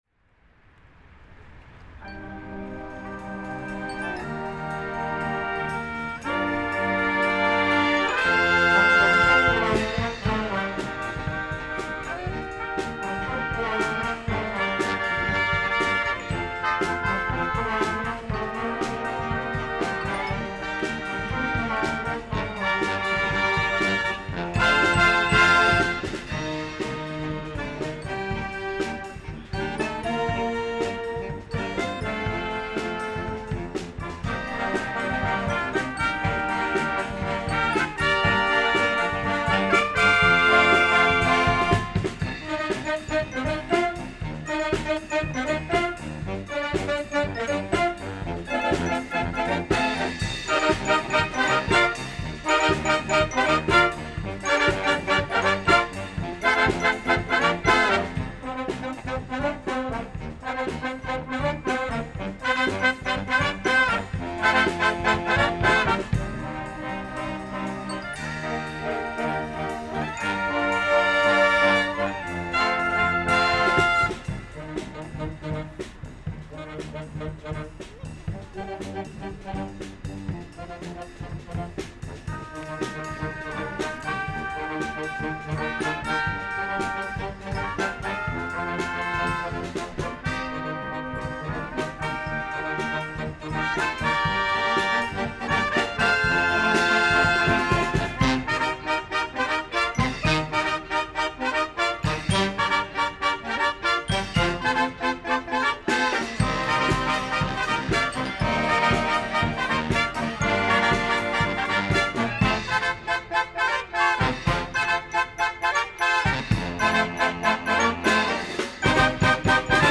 Outside La Moneda palace in Santiago
tuning in to the ceremonial changing of the guard
the accompanying military band breaks into a cover